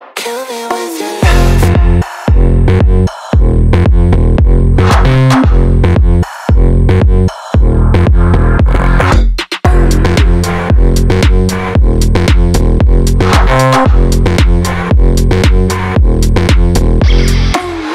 • Качество: 320, Stereo
громкие
мощные
женский голос
Electronic
качающие
Стиль: bass house